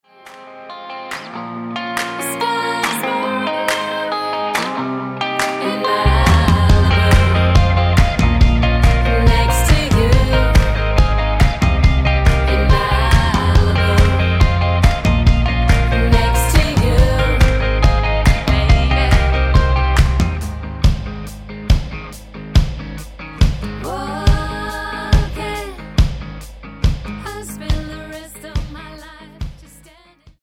--> MP3 Demo abspielen...
Tonart:Ab mit Chor